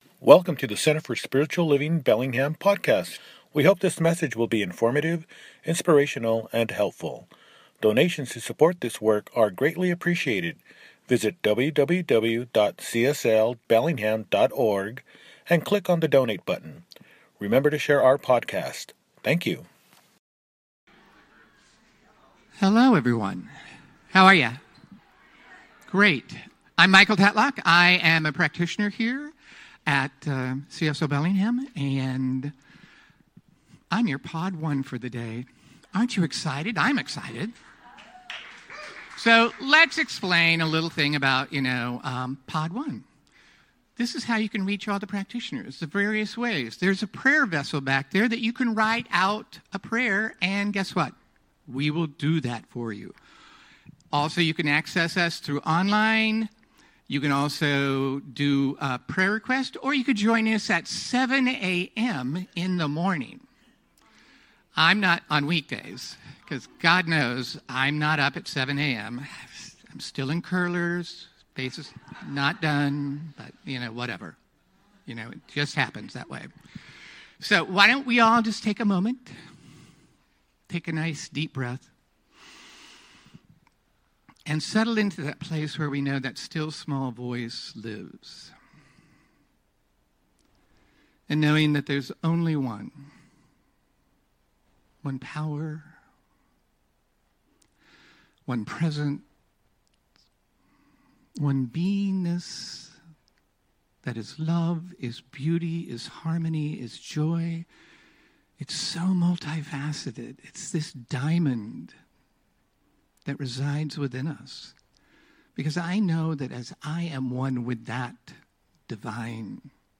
Living in the Field of Love – Celebration Service